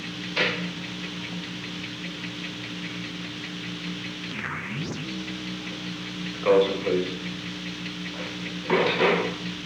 The Old Executive Office Building taping system captured this recording, which is known as Conversation 295-011 of the White House Tapes. Nixon Library Finding Aid: Conversation No. 295-11 Date: November 16, 1971 Time: Unknown between 3:09 pm and 3:16 pm Location: Executive Office Building The President talked with the White House operator.